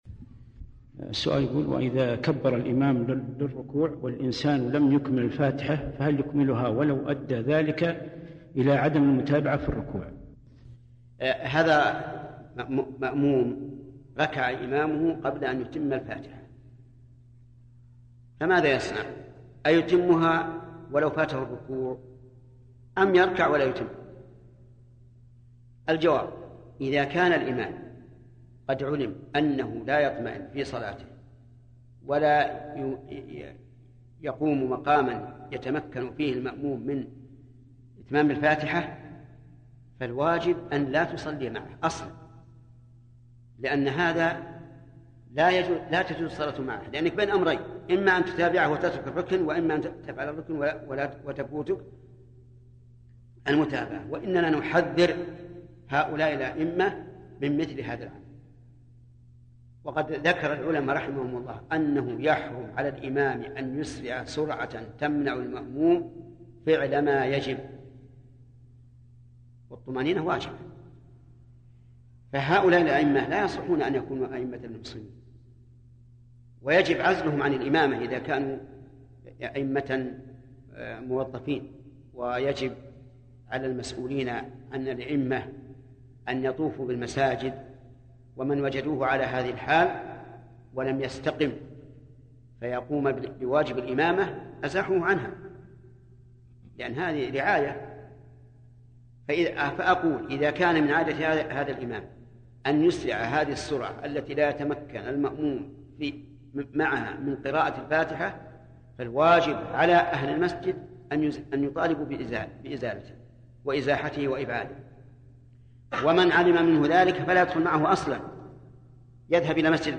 المصدر: سلسلة لقاءات الباب المفتوح > لقاء الباب المفتوح [١٤٦]